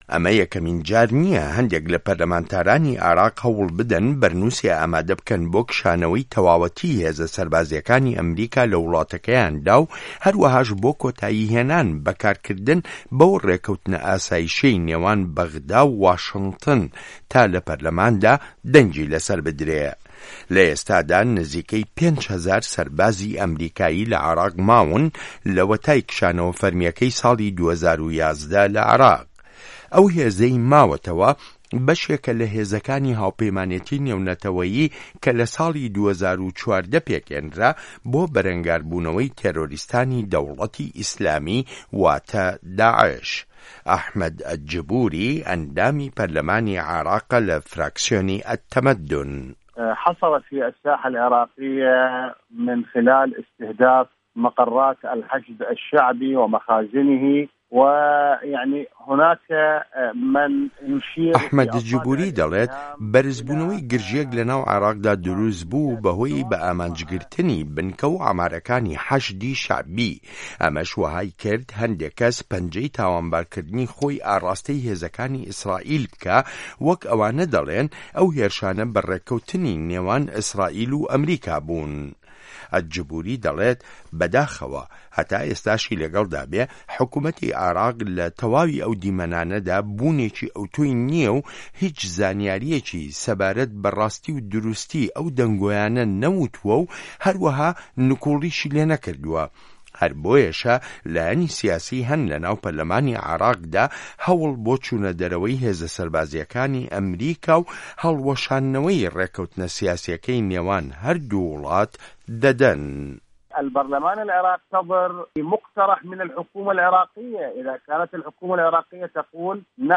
ڕاپۆرتی عێراقیەکان و کشانەوەی ئەمریکا لە وڵاتەکەیاندا